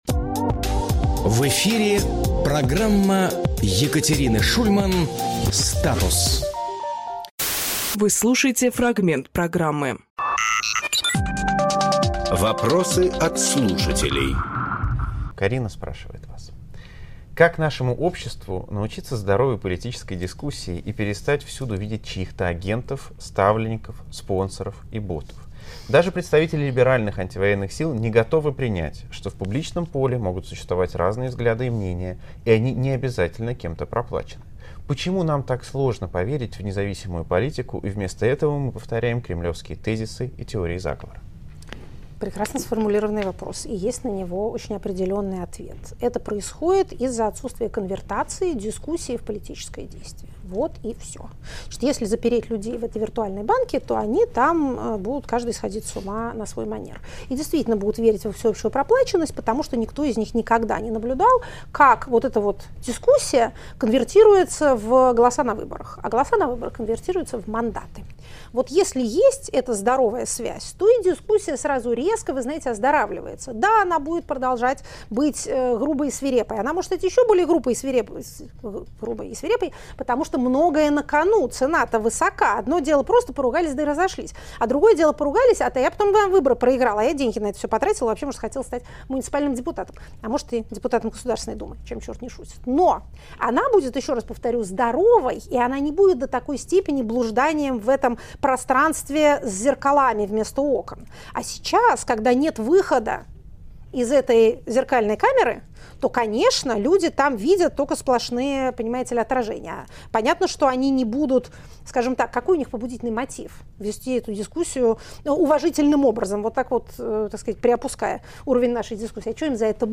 Фрагмент эфира от 19 ноября.